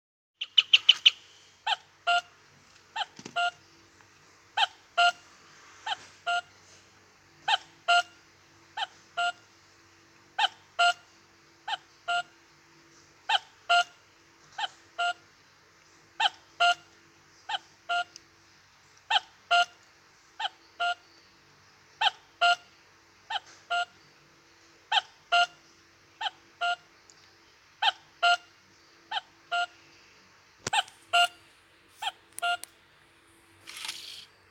Meccanismo orologio a cucu al quarzo con pendolo.
Descrizione: Movimento pendolo CU-CU' "TIME CONTROL"
Cucu_senza_ruscello.mp3